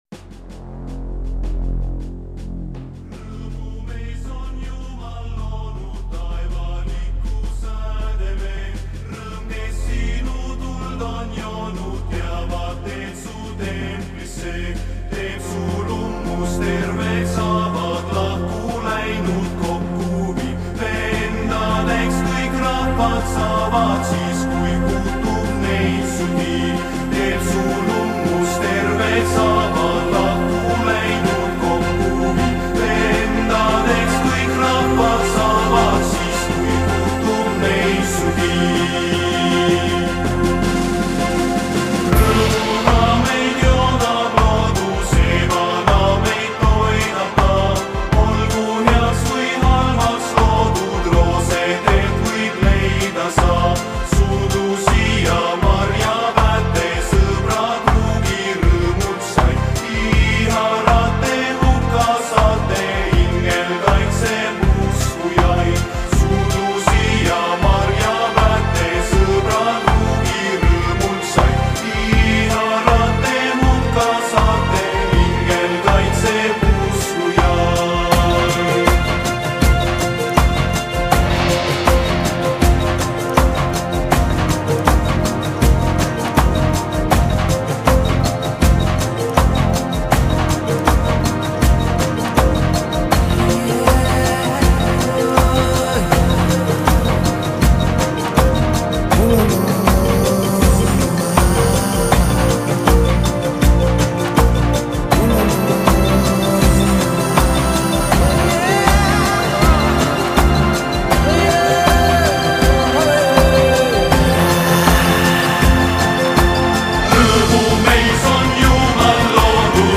这张继续了上张专辑的欢快风格